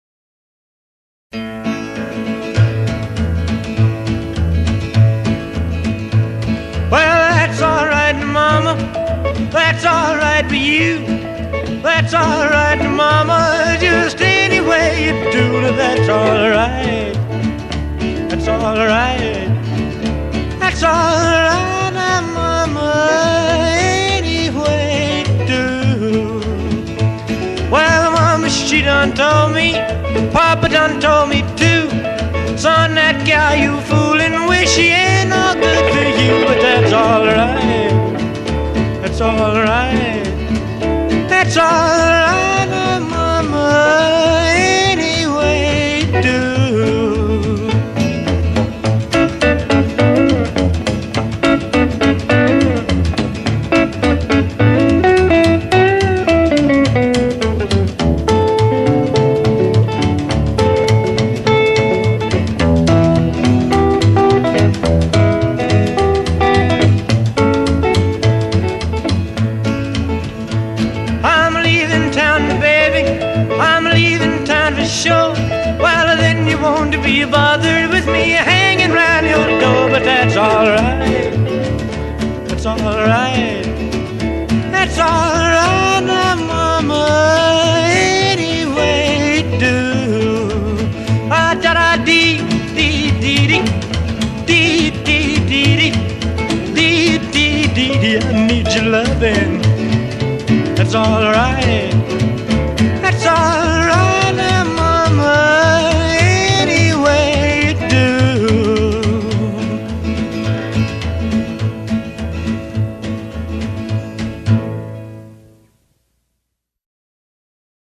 Rock and Roll, Pop